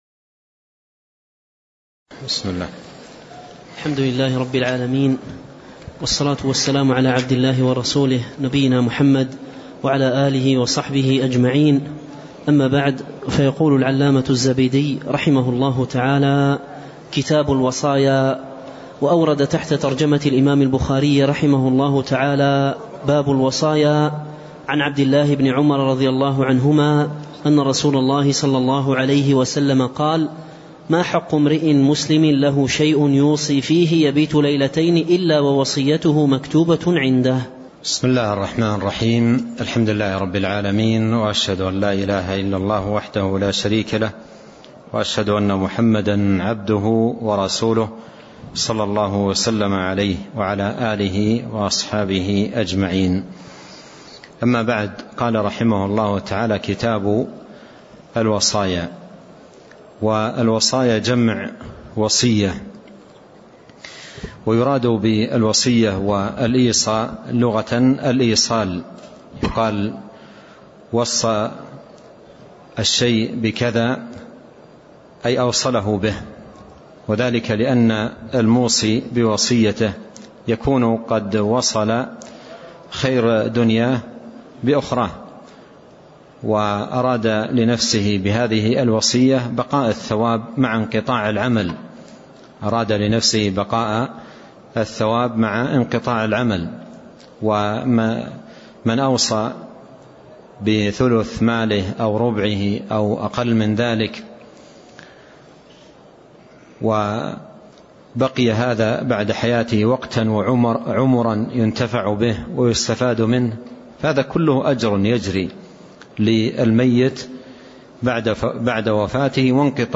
تاريخ النشر ٦ صفر ١٤٣٥ هـ المكان: المسجد النبوي الشيخ